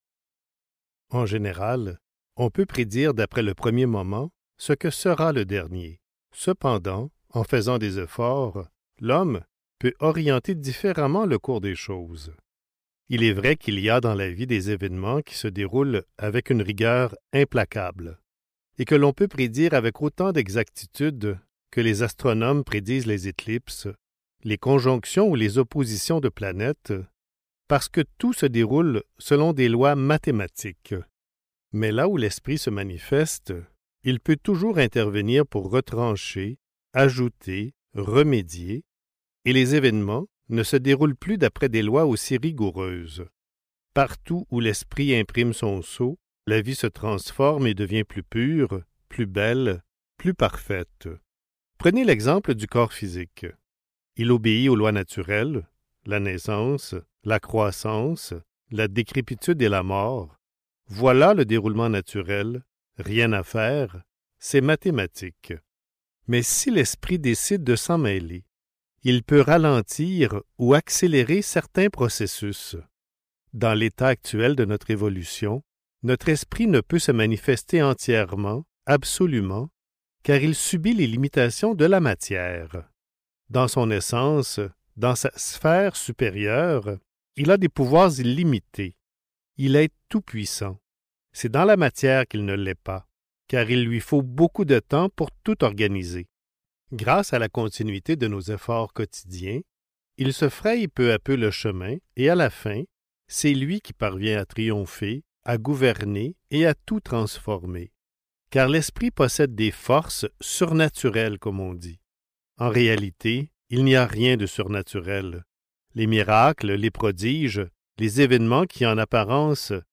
La liberté, victoire de l'esprit (Livre audio | CD MP3) | Omraam Mikhaël Aïvanhov